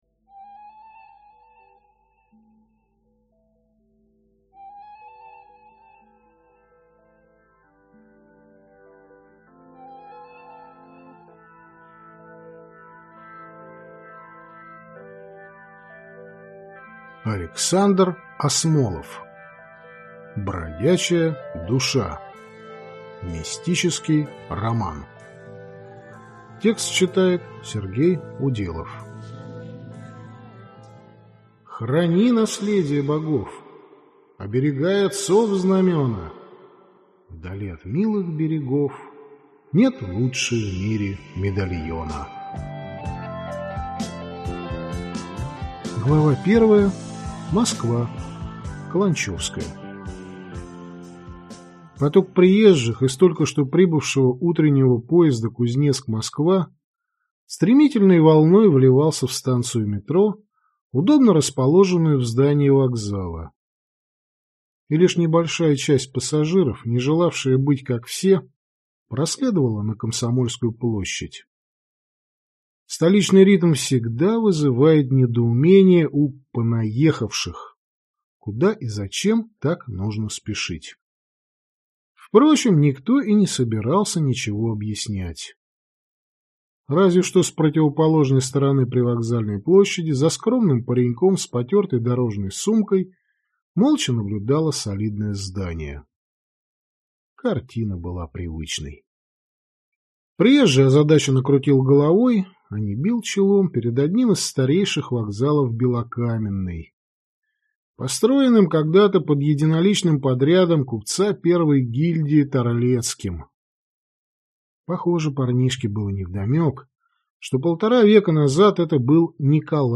Аудиокнига Бродячая душа | Библиотека аудиокниг